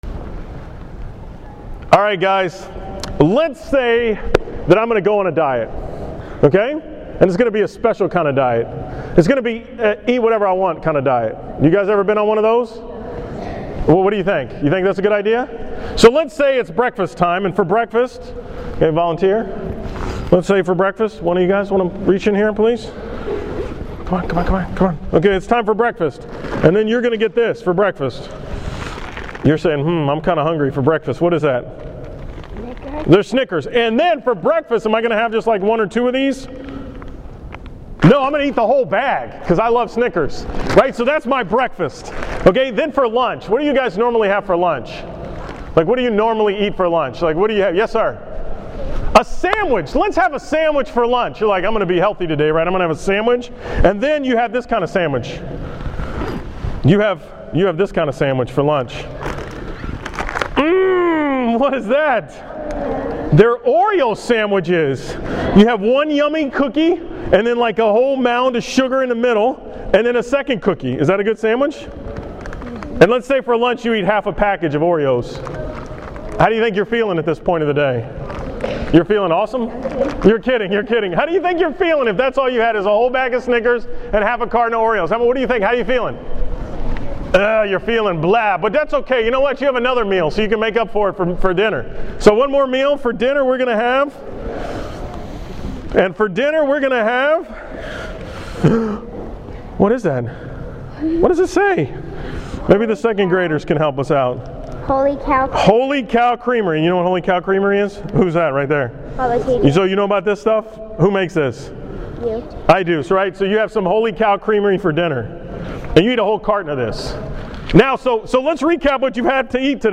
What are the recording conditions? Hopefully, it is a diet of Jesus! This is the homily for the school Mass at St. Thomas More on May 9, 2014.